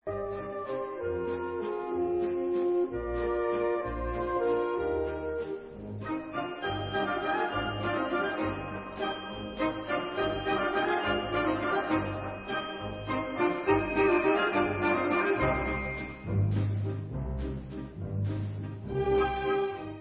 Recording: ORCHESTRAL MUSIC